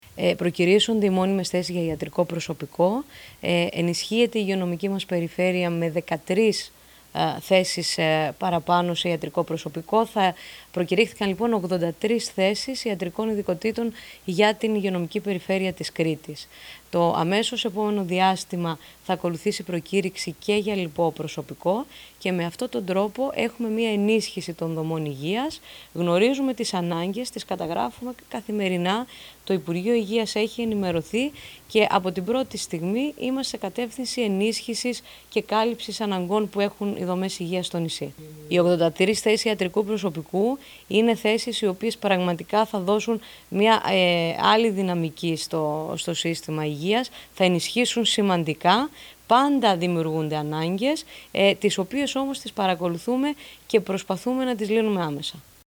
H Διοικήτρια της 7ης ΥΠΕ ΚΡΗΤΗΣ
Ακούστε εδώ τις δηλώσεις της κ. Μπορμουδάκη